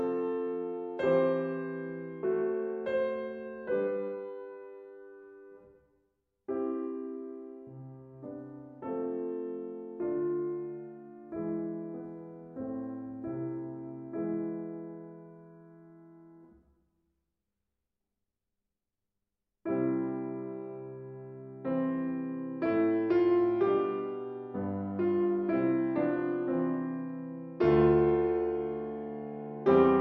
Angelus, Sacred piano music
Liszt Franz - 1811-1886 - piano